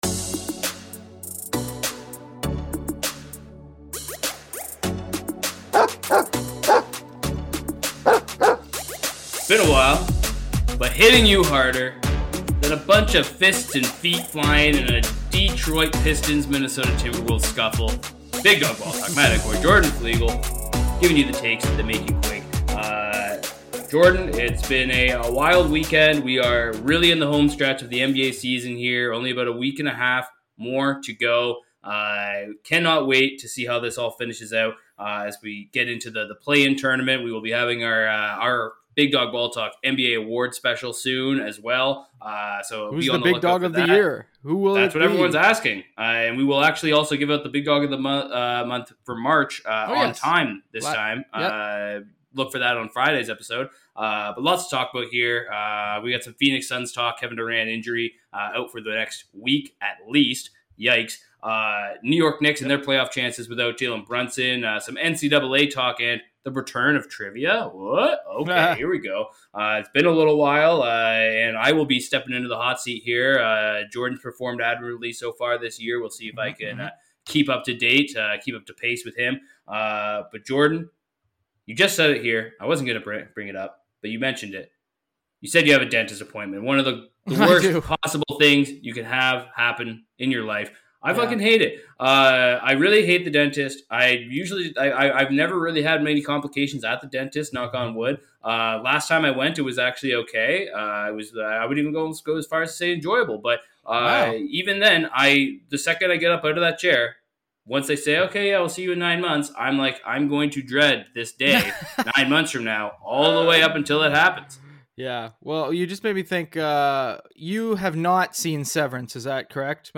In this conversation, the hosts delve into the controversies surrounding the NBA All-Star Game, discussing the league's handling of player participation and the overall attitude of players towards competition. They express disappointment in the lack of effort shown by players during the All-Star events, particularly the Dunk Contest, while also highlighting standout performances.